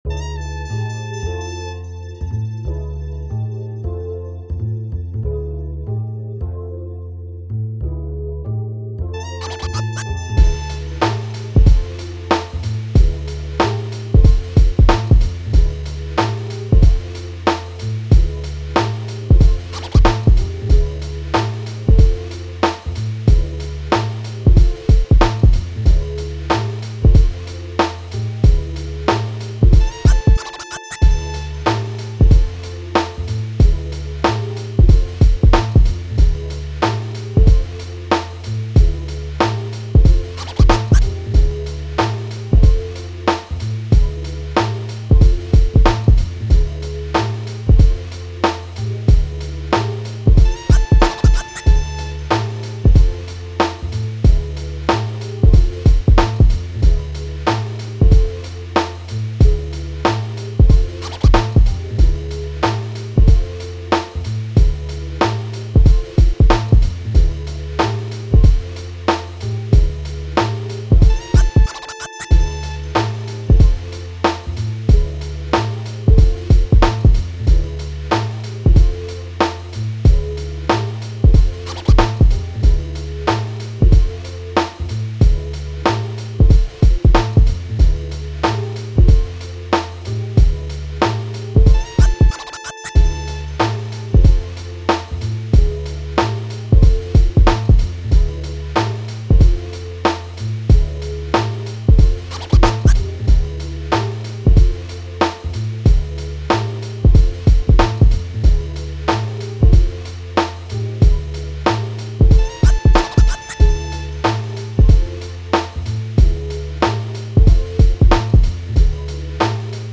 Hip Hop, 80s
D minor